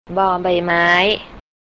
Pronunciation
บอ-ใบ-ไม้
bor bai-mai